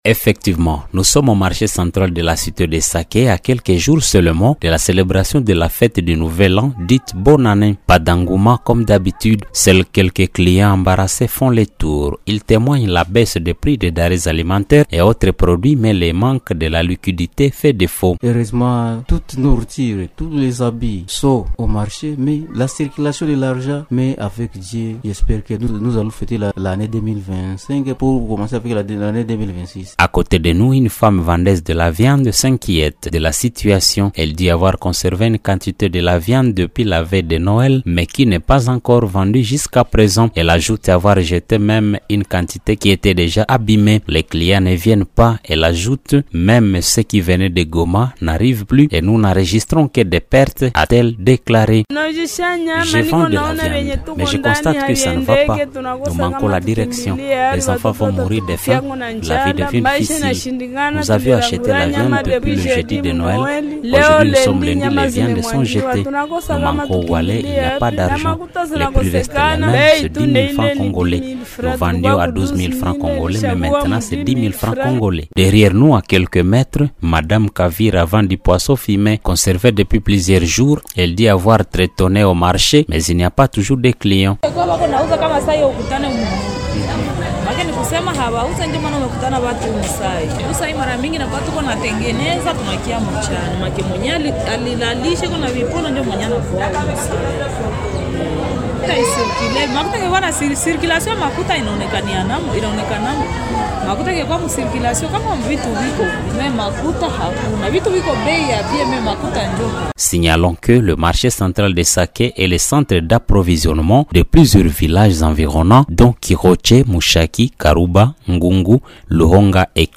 Rencontrées sur le Marché, certaines personnes se disent très satisfaites car cela accroît le pouvoir d’achat, mais s’inquiètent cependant de la non liquidité.
À côté, une femme vendeuse de la viande s’inquiète de la situation.